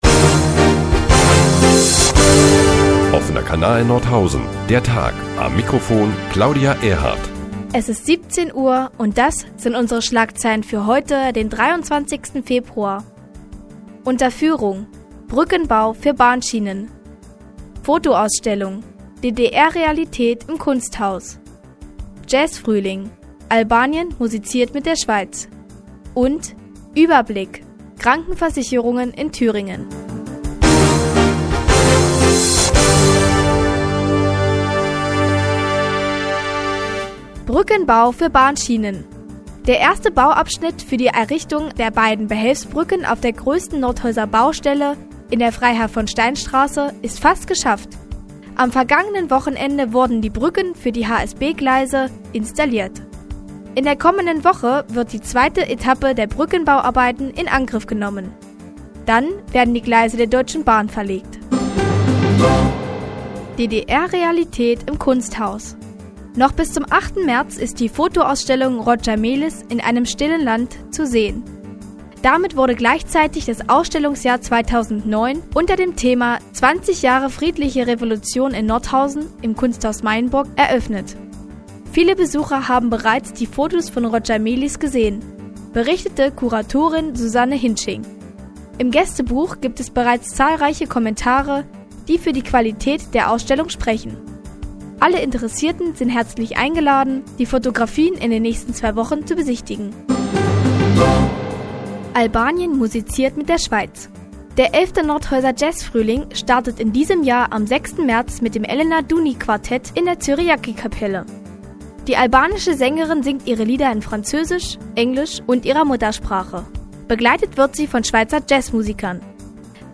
Die tägliche Nachrichtensendung des OKN ist nun auch in der nnz zu hören. Heute unter anderem mit dem Brückenbau für Bahnschienen und DDR- Realität im Kunsthaus.